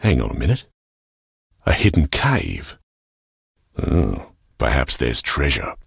Hidden_cave.ogg